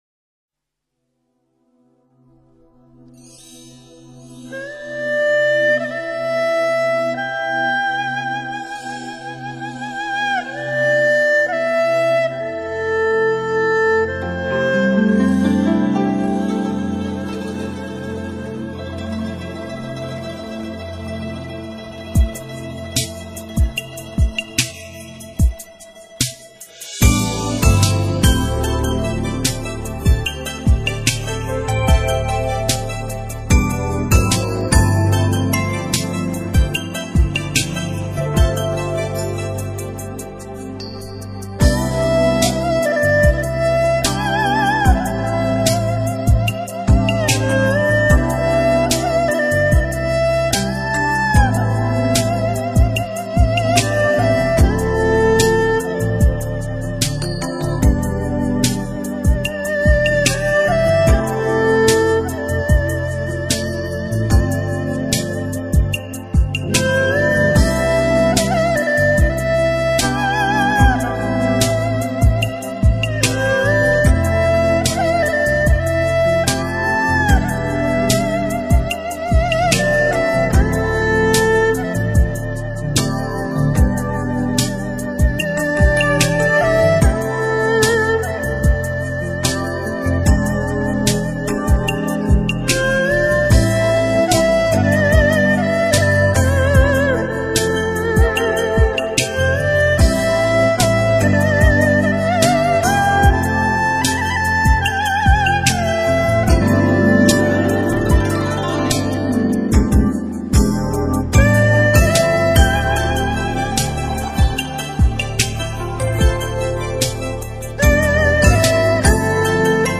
“月”的主题在筝弦轻拨间再次得到烘托